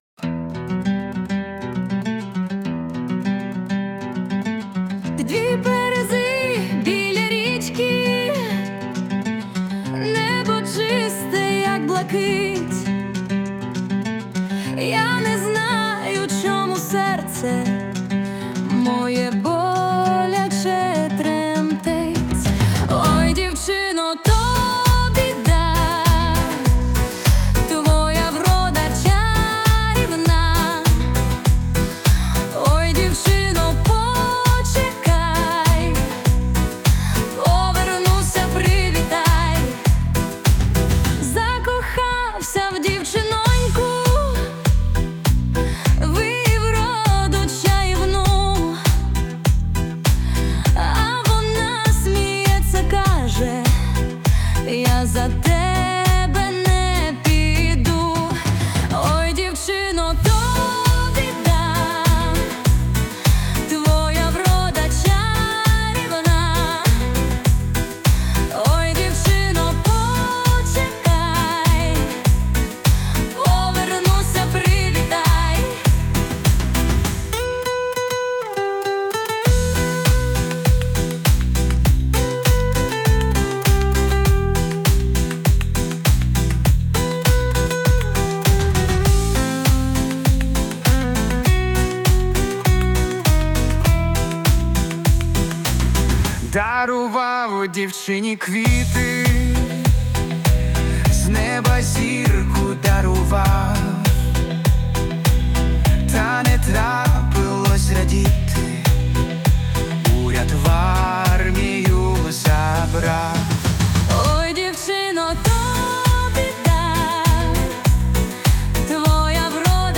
Ой, дівчино(дует)_1.mp3
Музика та голос =SUNO
СТИЛЬОВІ ЖАНРИ: Ліричний
ВИД ТВОРУ: Пісня